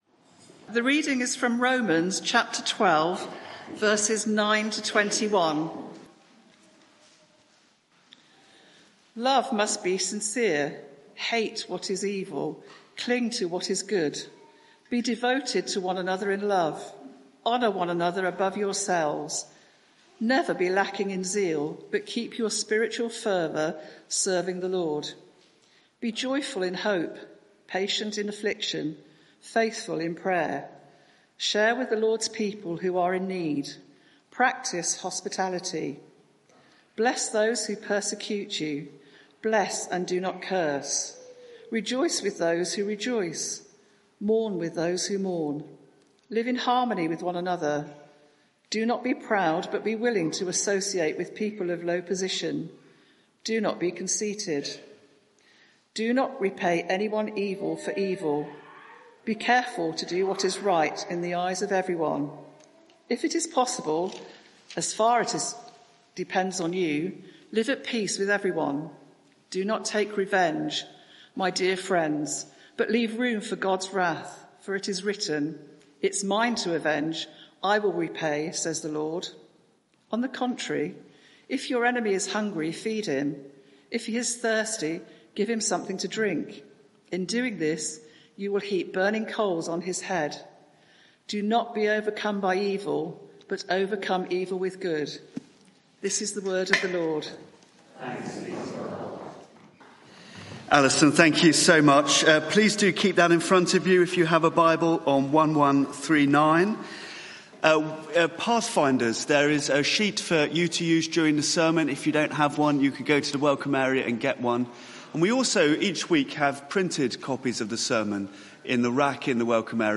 Media for 11am Service on Sun 27th Oct 2024 11:00 Speaker
Sermon